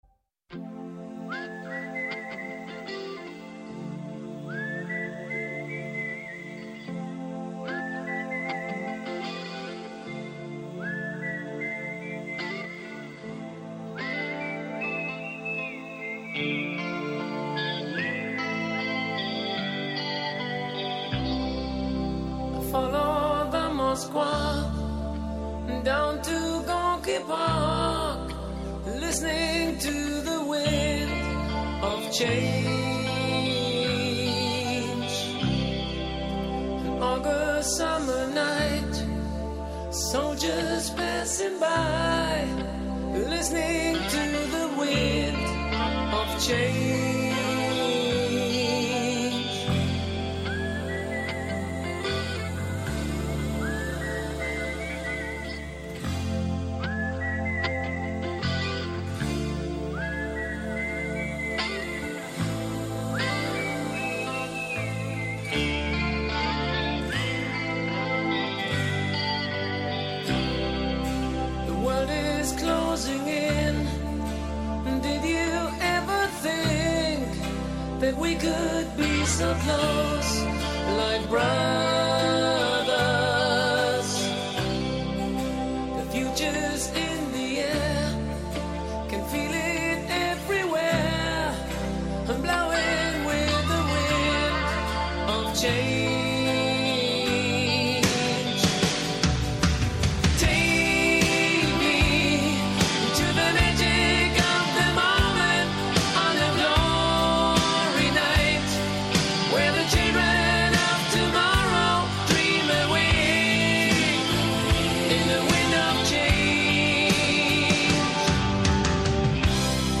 ο Νίκος Χριστοδουλάκης, ομότιμος καθηγητής του Οικονομικού Πανεπιστημίου, πρώην υπουργός Οικονομικών και επικεφαλής του Ινστιτούτου «In Social» (Ινστιτούτο για τη Σοσιαλδημοκρατία)